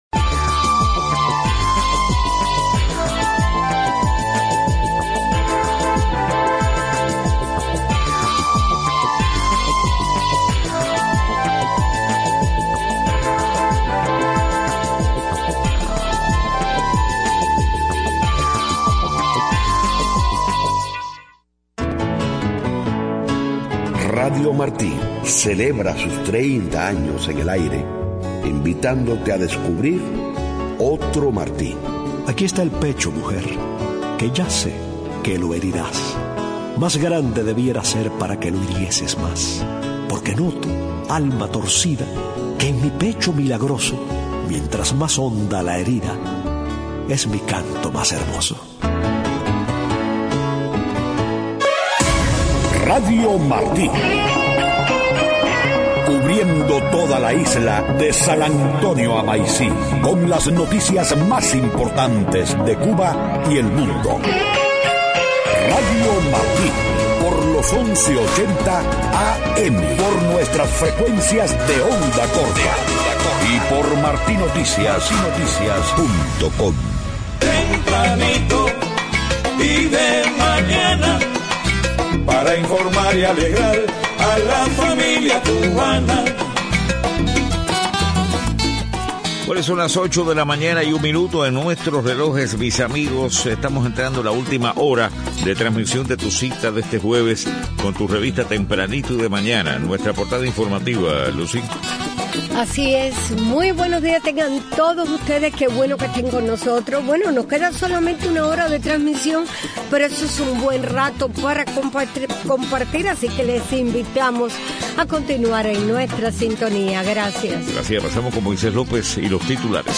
7:00 a.m Noticias: Periódicos alemanes exhortan a acelerar el acercamiento de la UE con Cuba. Empresa Pay Pal, de EEUU, pagará 7 millones 700 mil dólares de multa por violaciones al embargo a Cuba, Irán y Sudán. Reanudan hoy EEUU y las potencias occidentales las negociaciones nucleares con Irán.